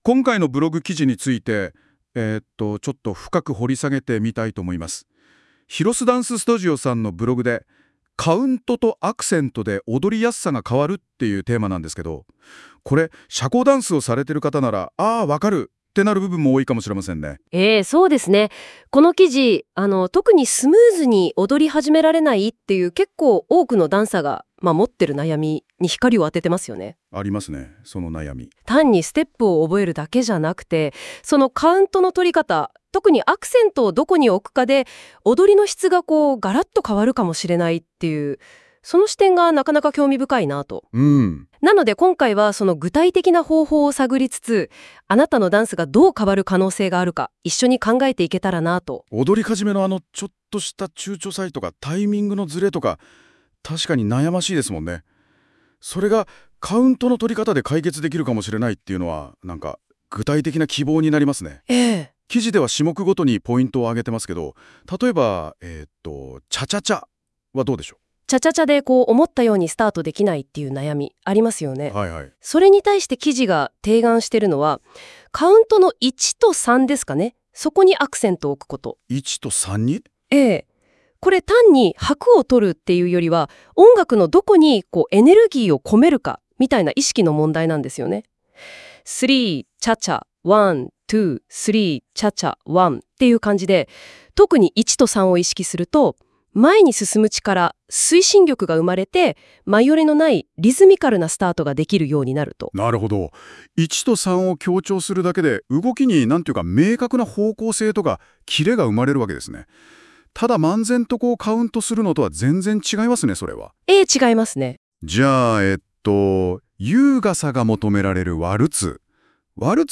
このブログについて、AIで生成した会話音声（約7分）も、お楽しみいただけます。